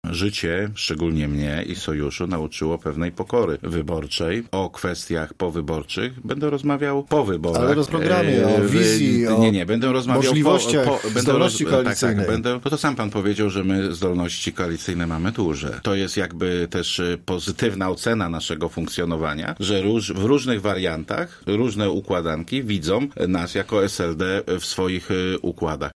Zdaniem Wontora, który był dziś gościem Radia Zachód, rozdział mandatów do sejmiku po październikowych wyborach może być różny.